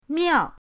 miao4.mp3